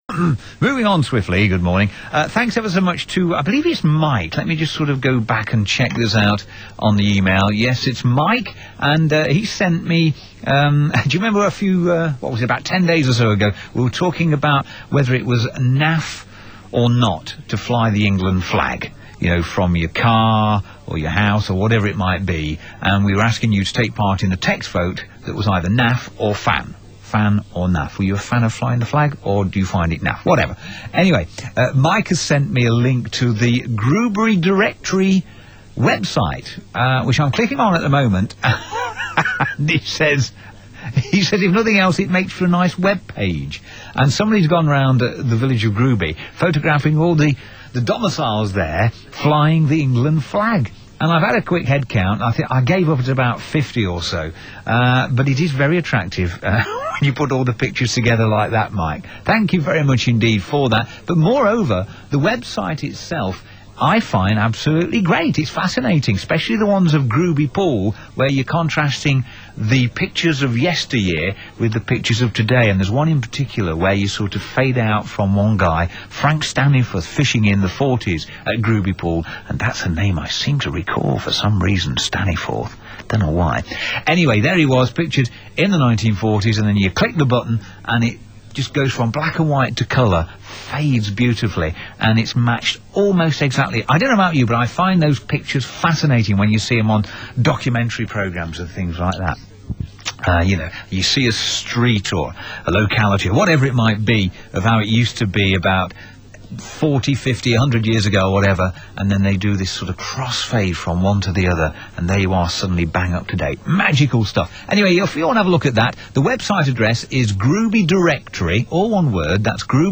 Featured on Radio Leicester 23.06.06